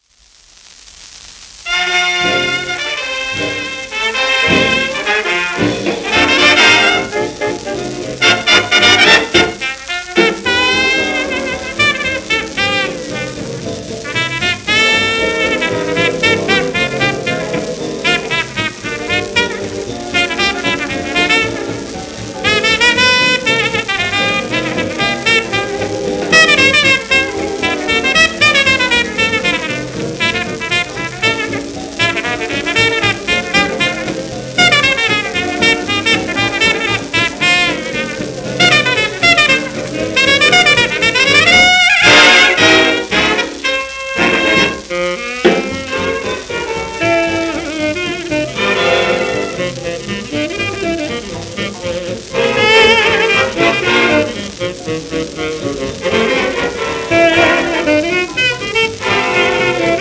1939年頃の録音